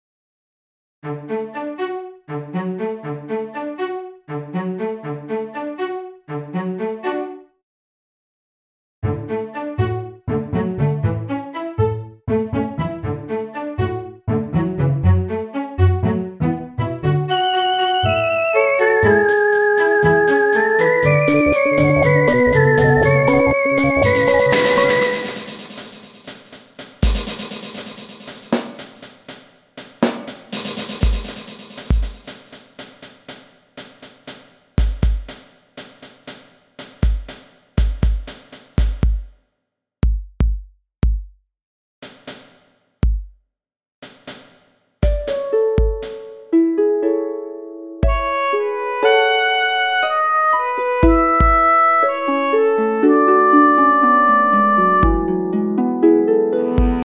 途中ドラムの音しか聞こえない部分がありますが、ご心配なく。